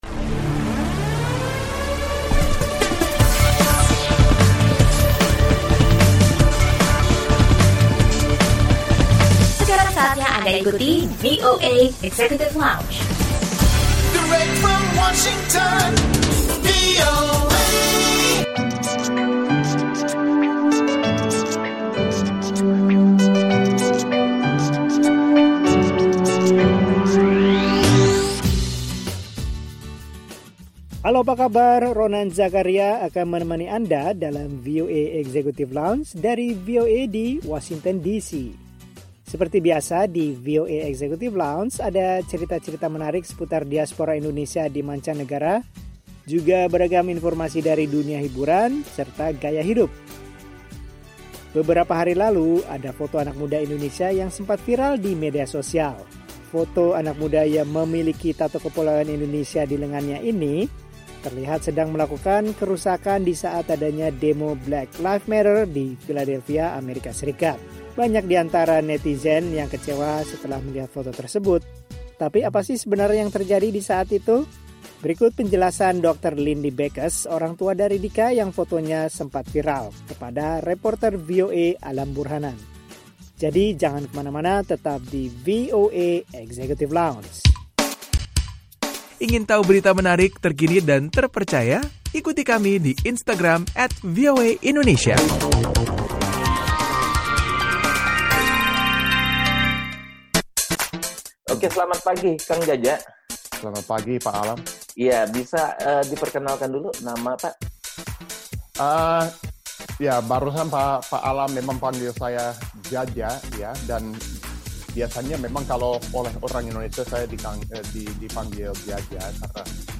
Obrolan